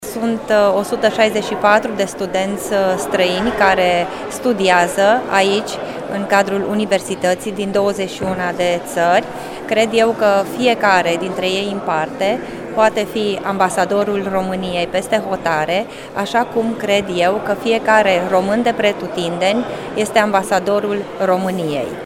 Ministrul pentru Românii de Pretutindeni, Natalia Intotero, a participat astăzi la o întâlnire cu studenţii Universităţii de Ştiinţe Agricole şi Medicină Veterinară a Banatului din Timişoara.